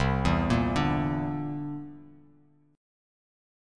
C9 Chord
Listen to C9 strummed